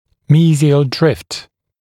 [‘miːzɪəl drɪft][‘ми:зиэл дрифт]самопроизвольное смещение зубов в мезиальном направлении, мезиальный дрейф